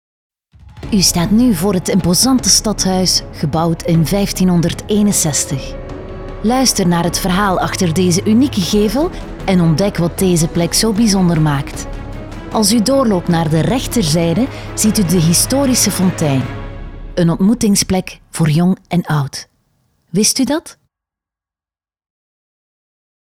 Natural, Cálida, Suave, Accesible, Amable
Audioguía